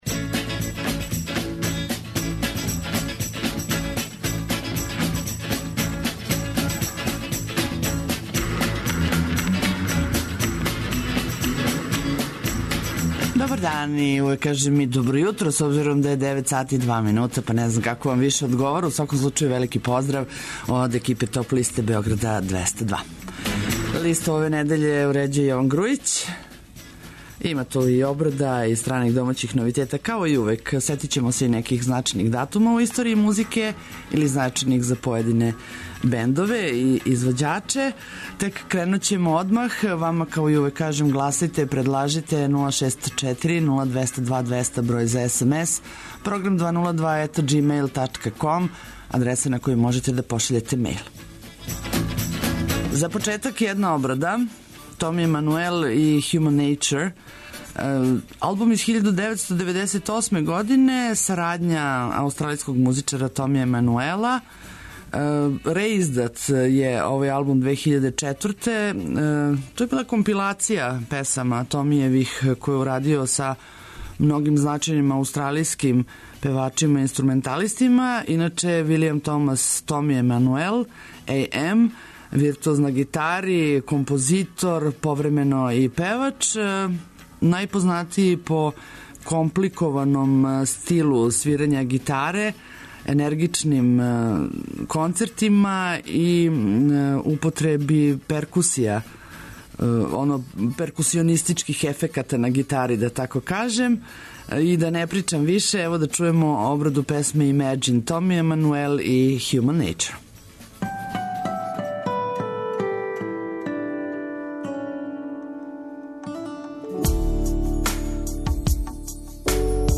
Најавићемо актуелне концерте у овом месецу, подсетићемо се шта се битно десило у историји рок музике у периоду од 07. до 11. септембра. Ту су и неизбежне подлисте лектире, обрада, домаћег и страног рока, филмске и инструменталне музике, попа, етно музике, блуза и џеза, као и класичне музике.